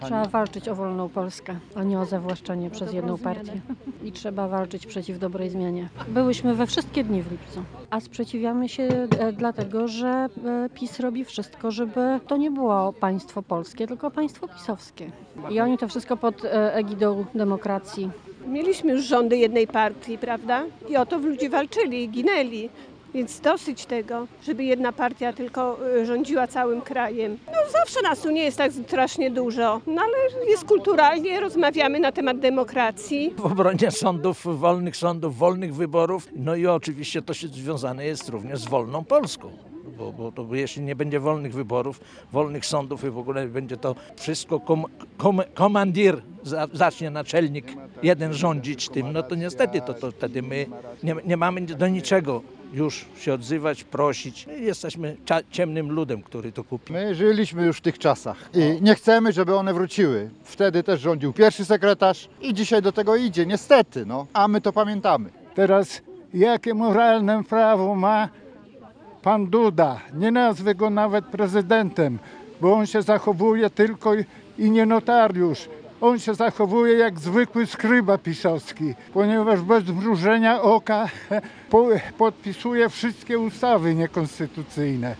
Około 30 osób protestowało w Ełku w ramach ogólnopolskiej akcji „Łańcuch Ludzi”. Mieszkańcy, którzy pojawili się w czwartek (14.12) punktualnie o 19.00 przed siedzibą Sądu Rejonowego, byli przeciwni działaniom rządu Prawa i Sprawiedliwości. Chodzi o reformy sądownictwa i zmiany w ordynacji wyborczej.
-Cofamy się do czasów sprzed 1989 roku, mówili reporterowi Radia 5 ełczanie.